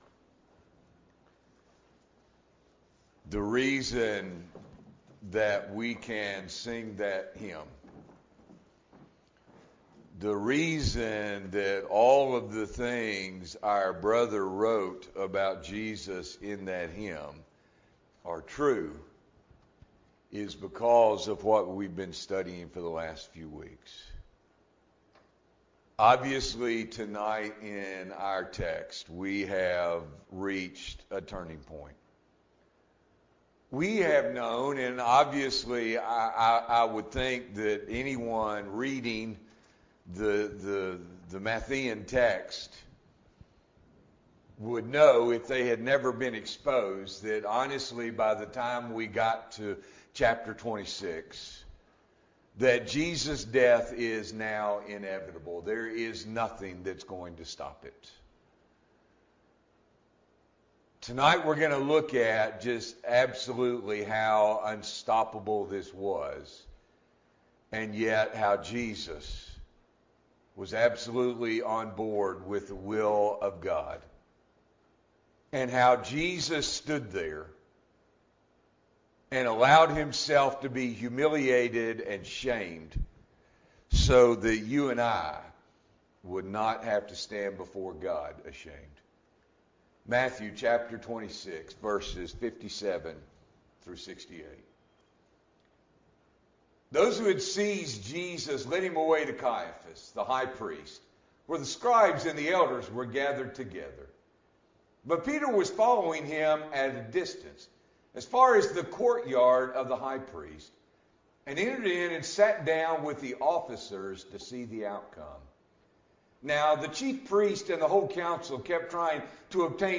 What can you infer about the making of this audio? February 14, 2021 – Evening Worship